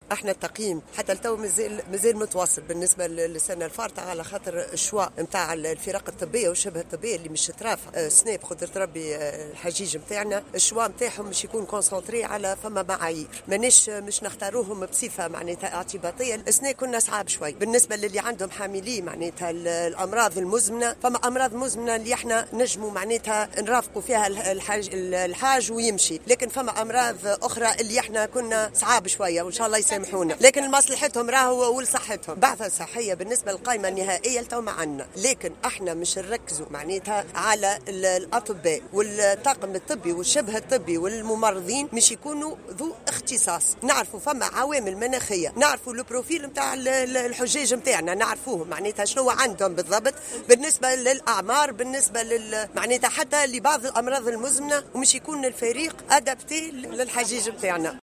وأضافت في تصريح لمراسلة الجوهرة اف ام اليوم الاثنين خلال حضورها افتتاح الشباك الموحد الخاص بإتمام إجراءات السفر للحج بجامع مالك ابن انس قرطاج، أن اختيار البعثة الطبية سيتم وفق معايير وليست بصفة اعتباطية.